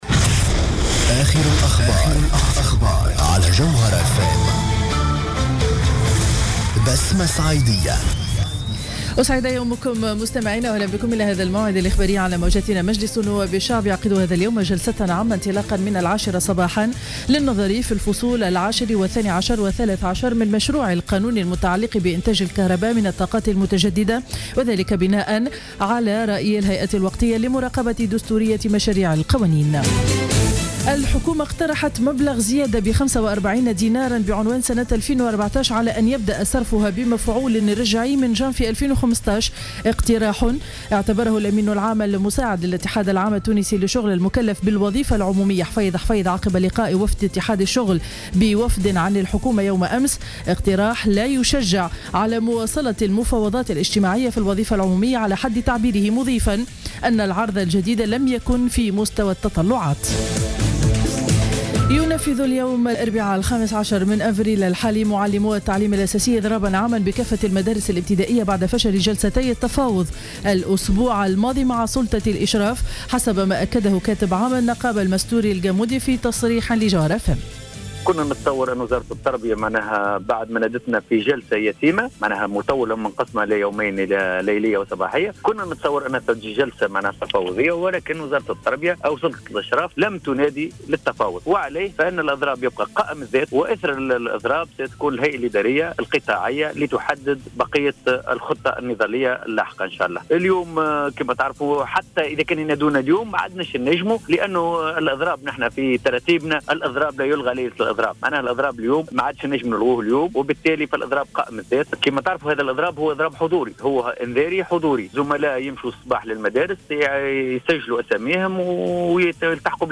نشرة أخبار السابعة صباحا ليوم الإربعاء 15 أفريل 2015